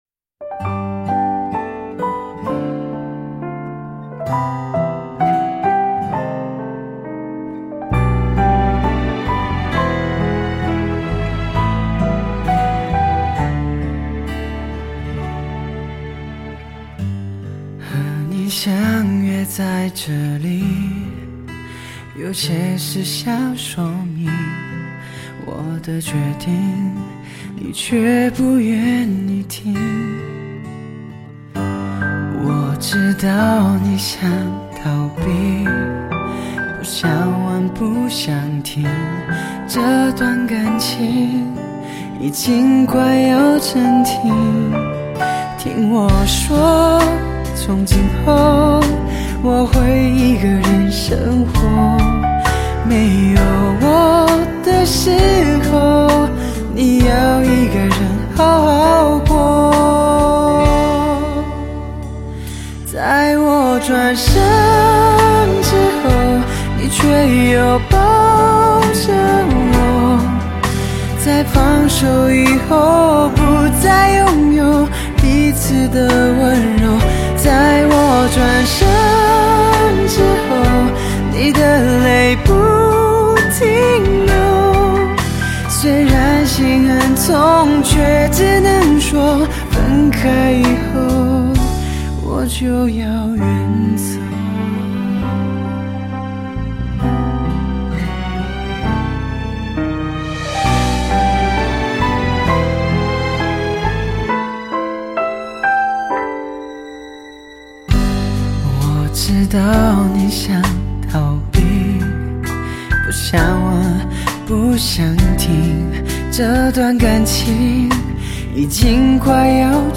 清新、清灵，这首歌听起来很随意，让人有种洒脱的感觉！
他有丝丝沙哑的唱腔
配合着假音，失落的音调